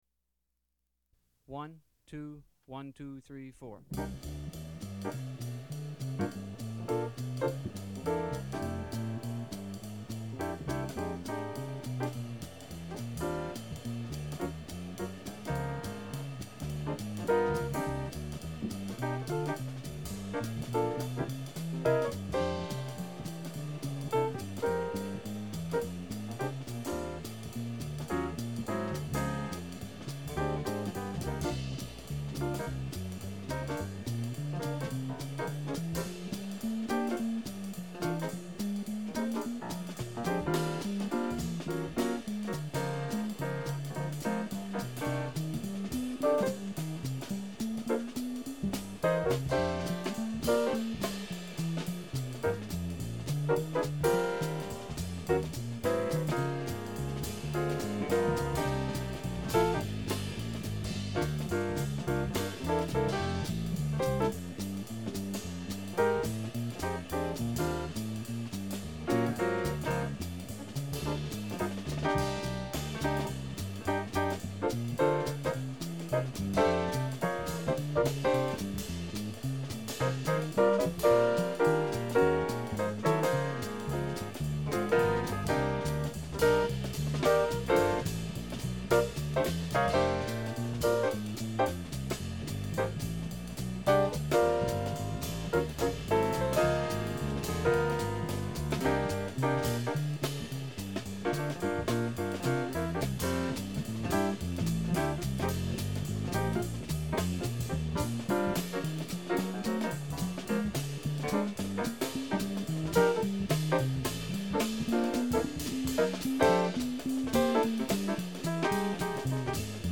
а также пример моего соло в трио.